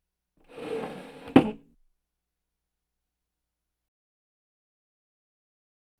household
Drawer Door Squeaky Closed